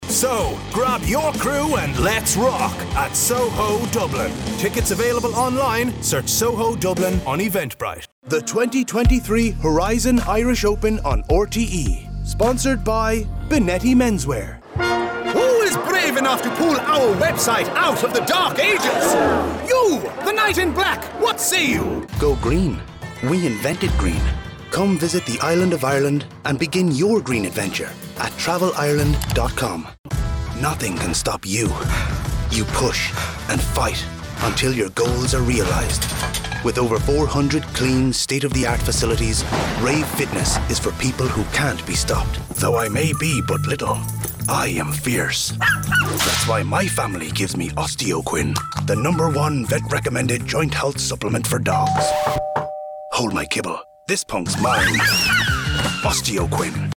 Englisch (Irland)
Kommerziell, Cool, Zuverlässig, Vielseitig, Zugänglich
Kommerziell